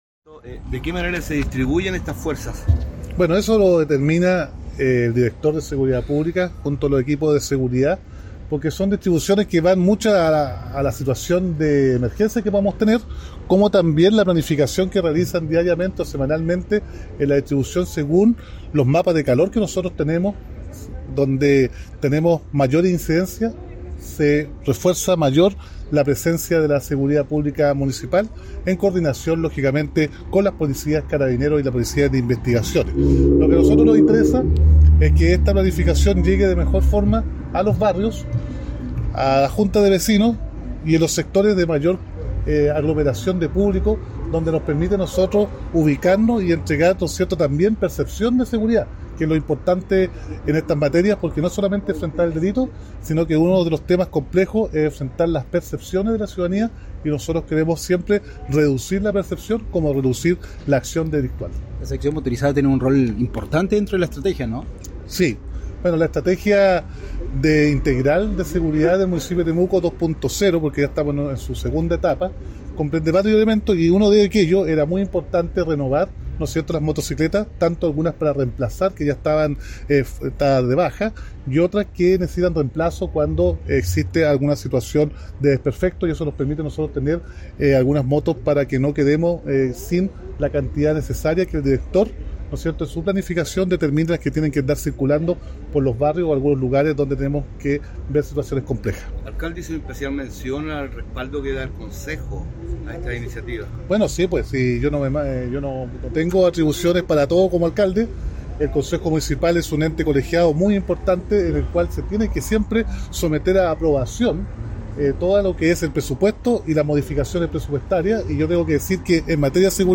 cuna-alcalde-motos.mp3